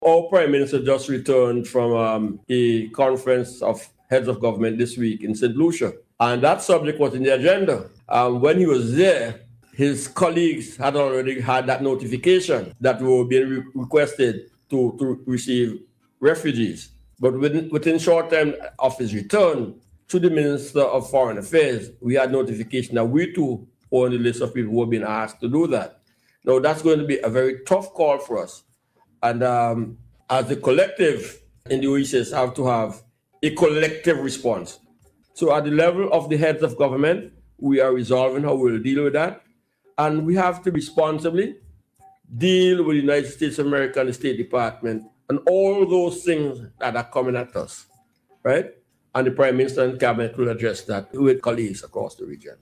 In addressing the issue on Radio yesterday, Deputy Prime Minister and Minister of National Security, Hon. St. Clair Leacock said this matter will require a collective response from the OECS.